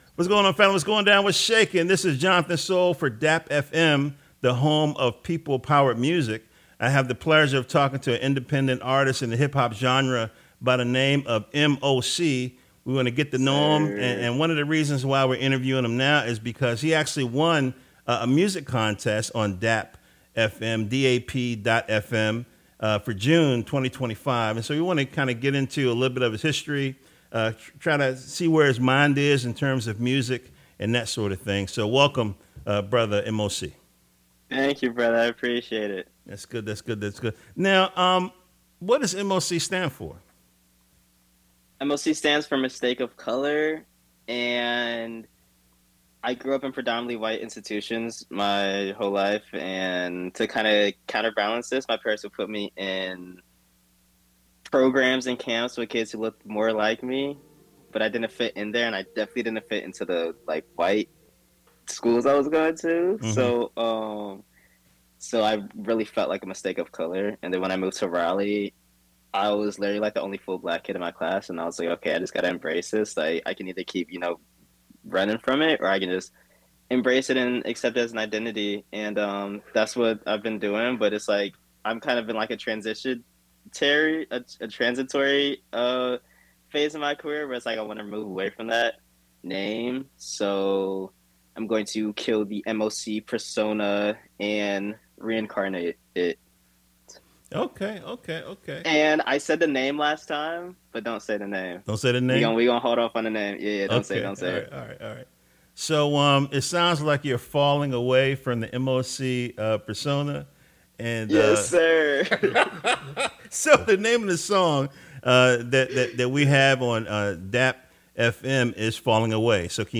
🎤 EXCLUSIVE INTERVIEW 🎤